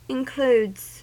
Ääntäminen
Ääntäminen US Tuntematon aksentti: IPA : /ɪnˈkluːdz/ Haettu sana löytyi näillä lähdekielillä: englanti Includes on sanan include monikko.